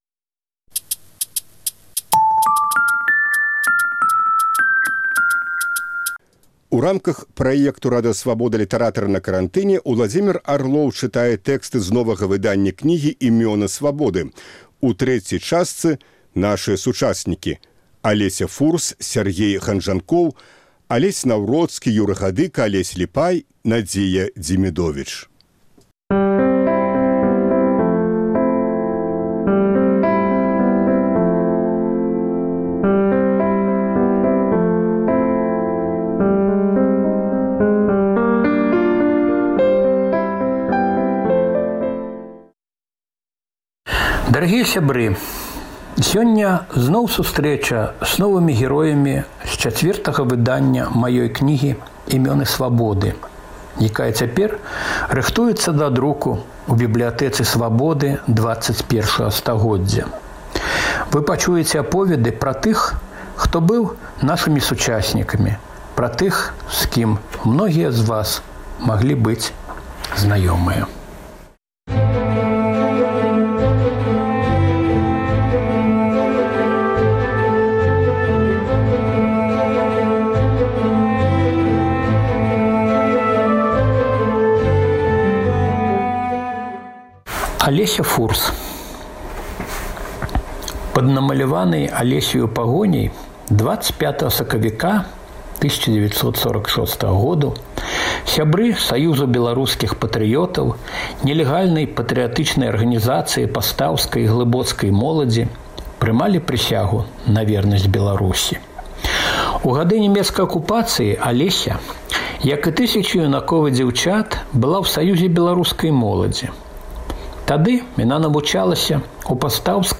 Kампэтэнтныя і крэатыўныя жанчыны абмяркоўваюць розныя тэмы, нечаканыя павароты, незвычайныя ракурсы.